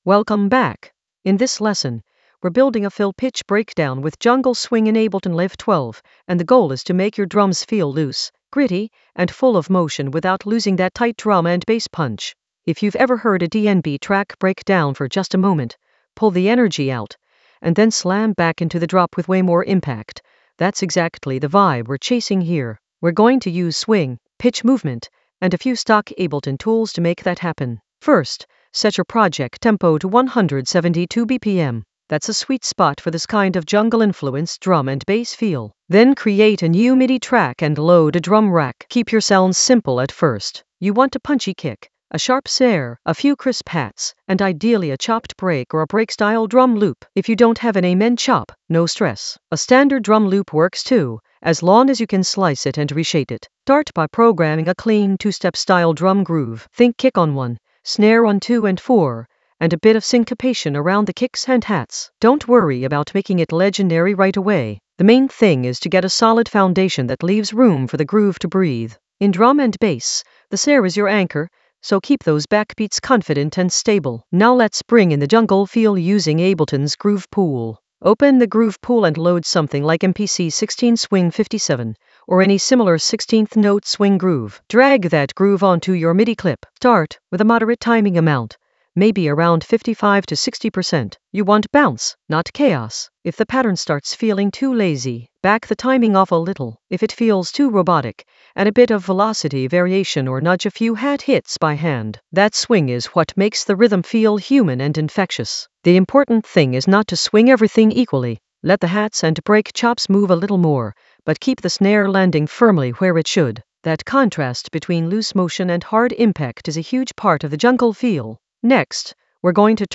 An AI-generated beginner Ableton lesson focused on Fill pitch breakdown with jungle swing in Ableton Live 12 in the Drums area of drum and bass production.
Narrated lesson audio
The voice track includes the tutorial plus extra teacher commentary.